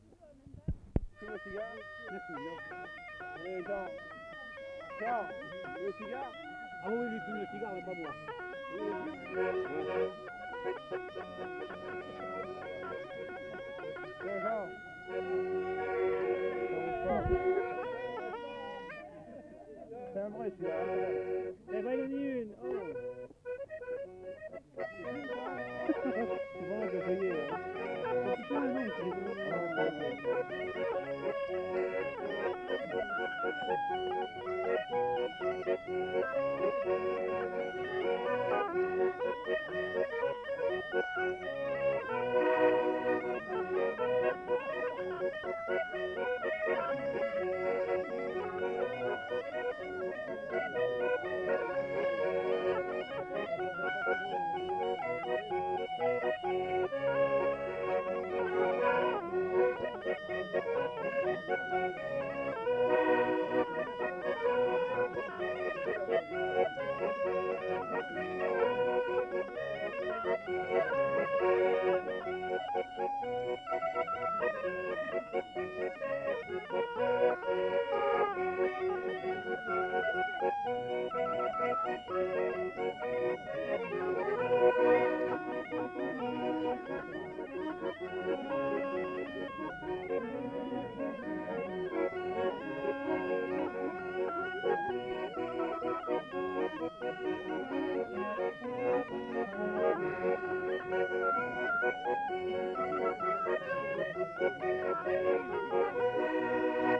Aire culturelle : Viadène
Genre : morceau instrumental
Instrument de musique : cabrette ; accordéon chromatique
Danse : valse